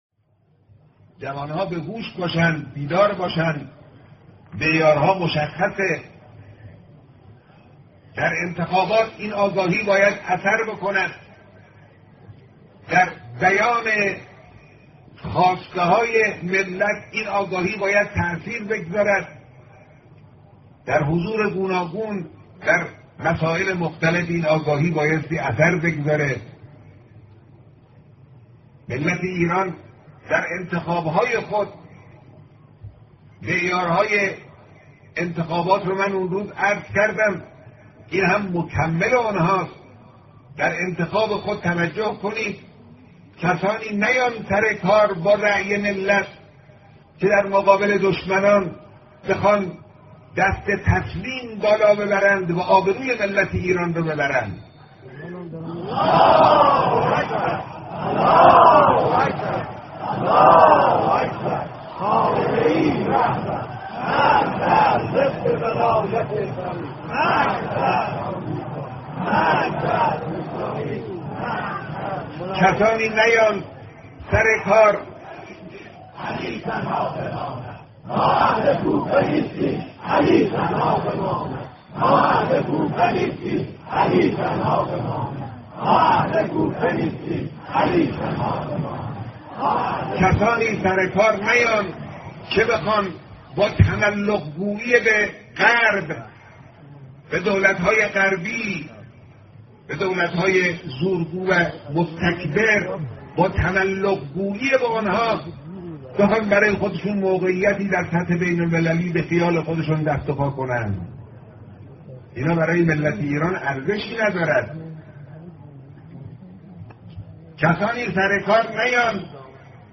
گزيده‌ای از بيانات رهبر انقلاب در مورد انتخابات / جوانان بهوش باشند